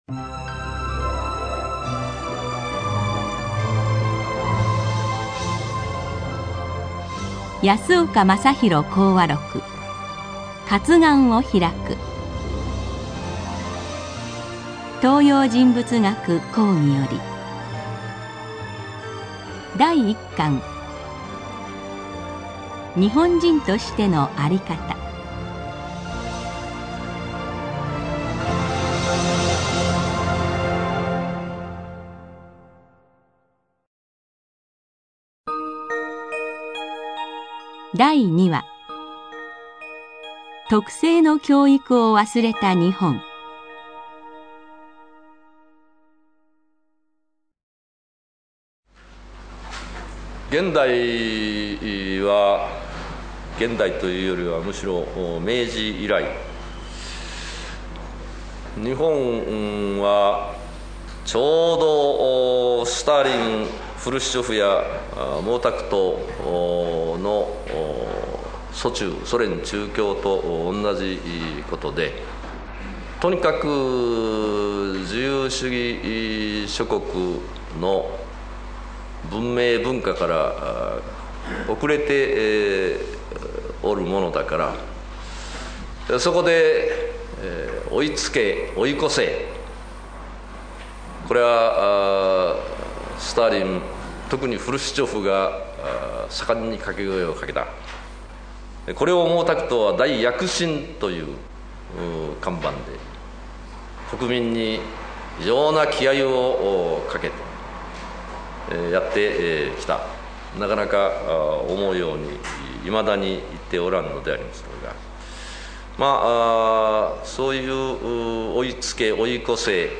CDに収録した音声のサンプルを試聴できます。
＜特長1＞安岡正篤氏が日本精神の危機を語った貴重な講話を肉声で収録。
また、講演当時の音声記録をベースとしているため、音声の一部に乱れがございます。